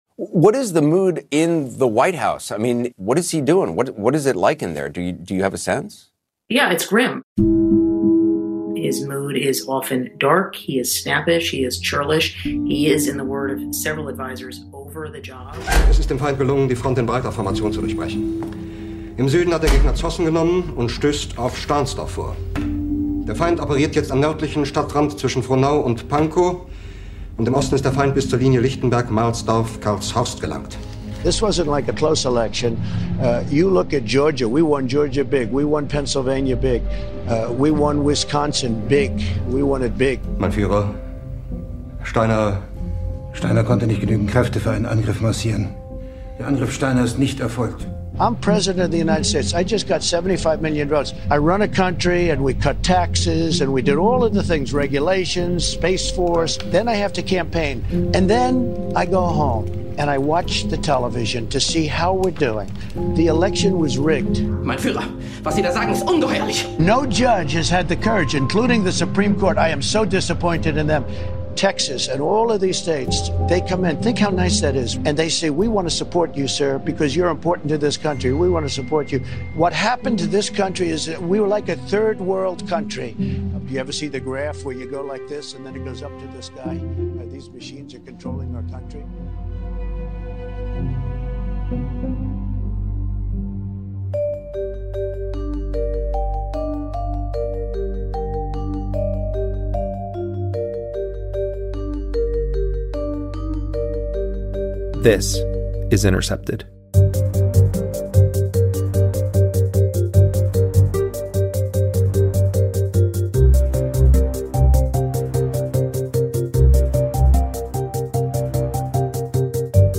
In a wide-ranging interview with Intercepted, Ocasio-Cortez discusses the fight for Medicare for All , the battle for the future of the Democratic Party, red-baiting and the 2020 election, Biden’s emerging Cabinet, disaster profiteering in Puerto Rico, the weaponizing of the Espionage Act, and more.